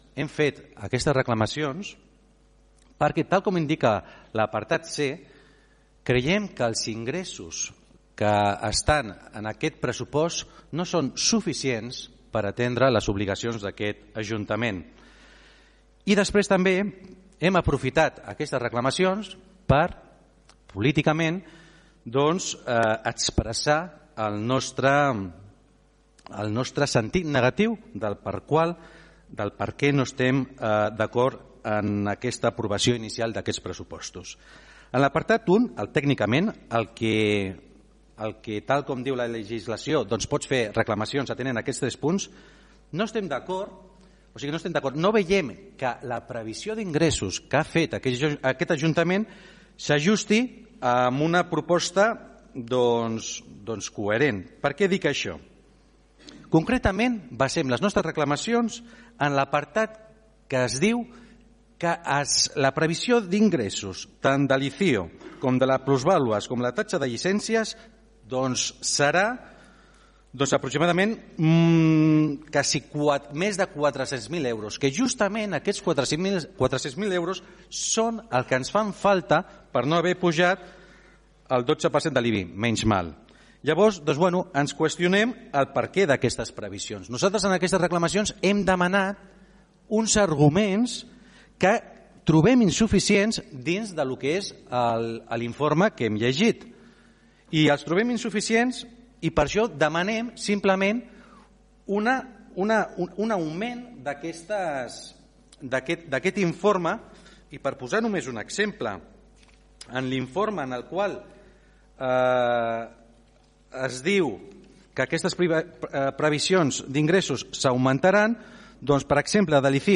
La principal reclamació del portaveu del PSC era que la previsió d’ingressos no s’ajustava a una proposta coherent. Santiago creia que eren “massa optimistes amb la previsió d’ingressos que heu fet, sobretot ICIO, plusvàlua i llicències”: